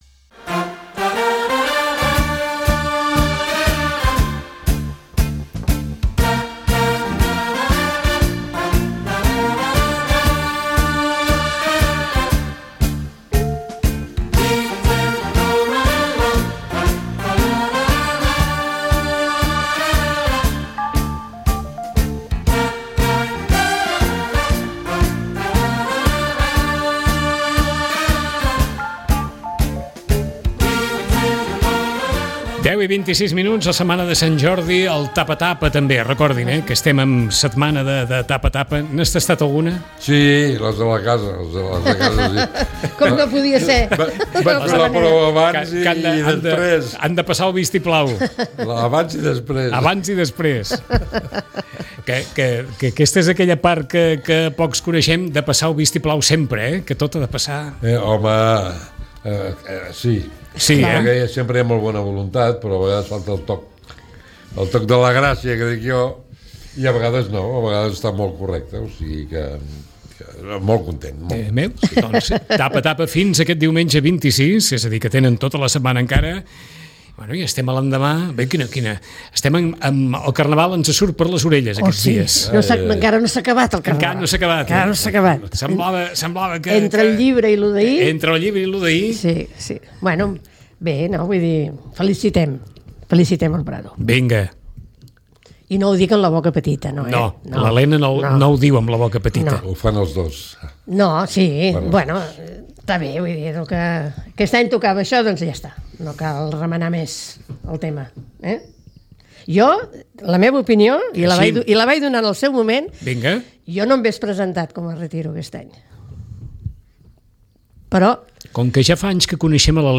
Tertúlia.